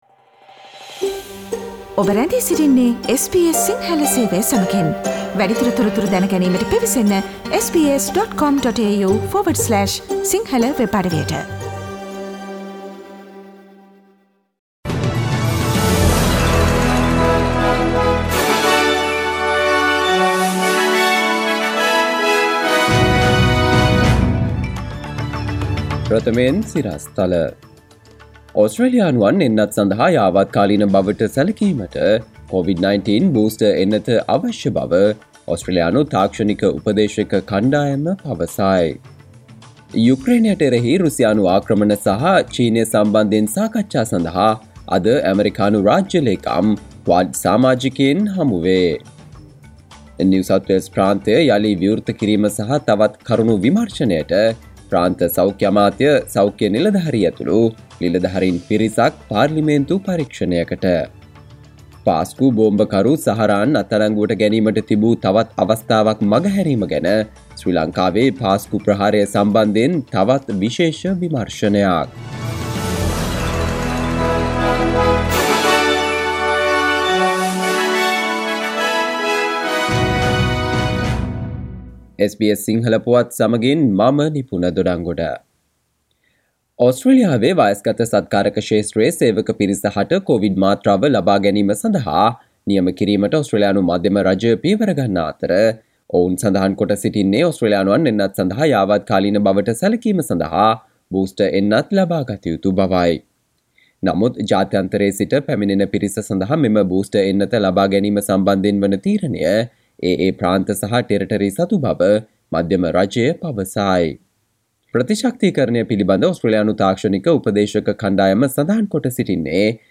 සවන්දෙන්න 2022 පෙබරවාරි 11 වන සිකුරාදා SBS සිංහල ගුවන්විදුලියේ ප්‍රවෘත්ති ප්‍රකාශයට...